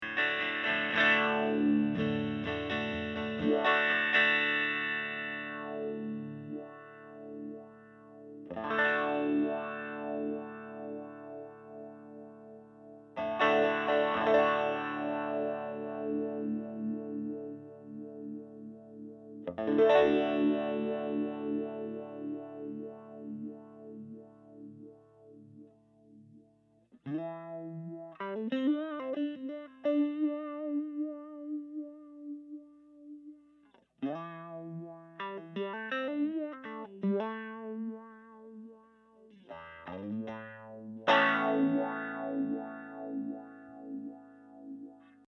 The sweep modes are ÒchoppyÓ and Òsmooth,Ó which refer to how quickly the sweep travels along the frequency range.
Sweeping the Speed control:  Clock Trigger, Smooth Mode